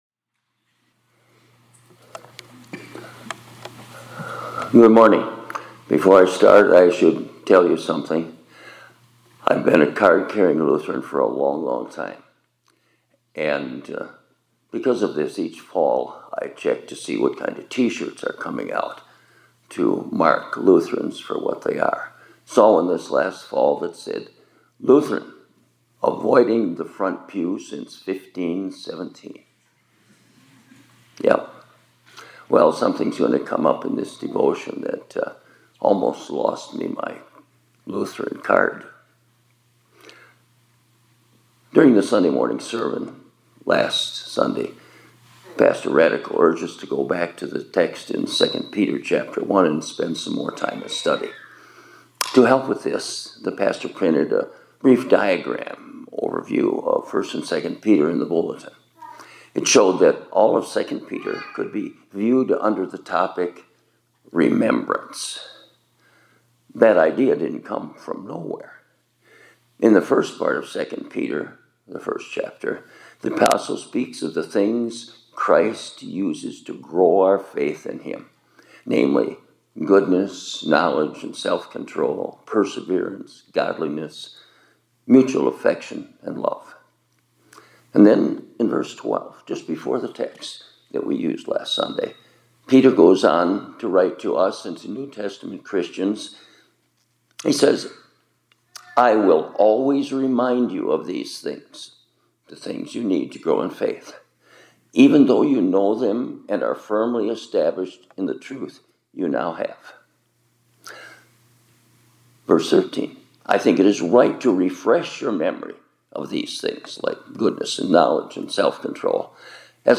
2025-02-11 ILC Chapel — Remember, Remember, Remember